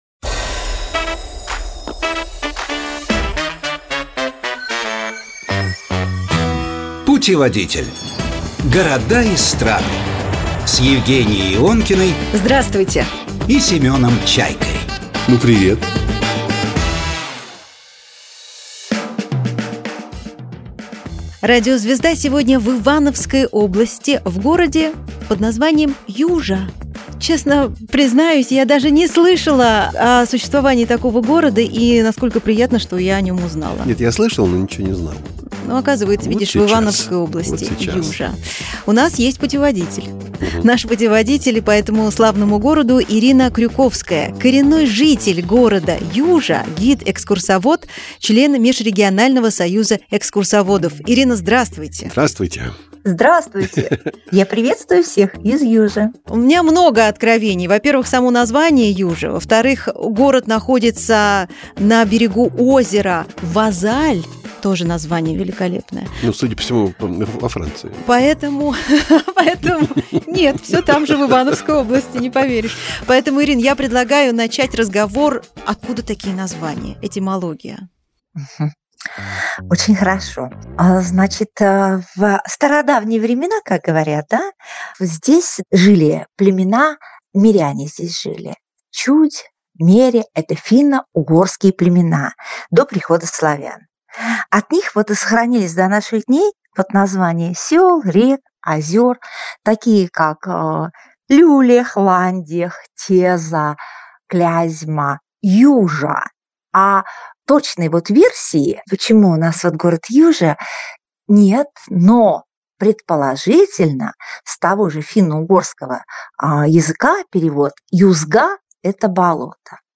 В студии программы «Путеводитель»